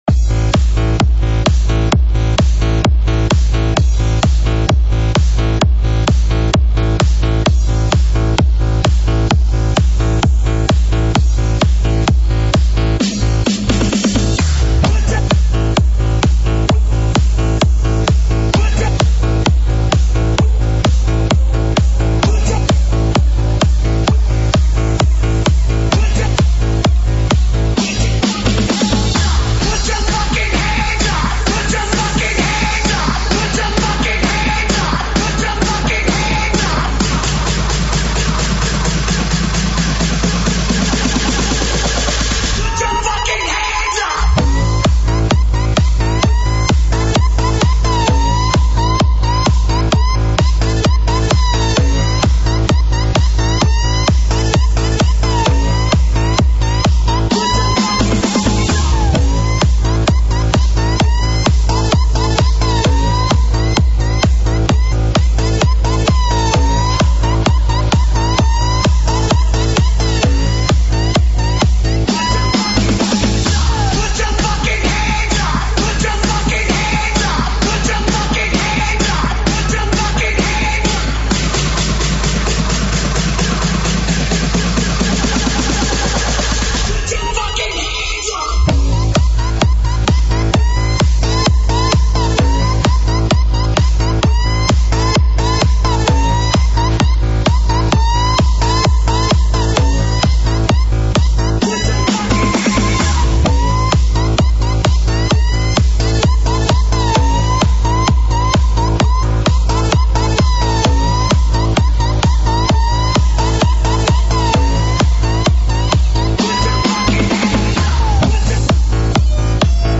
重低音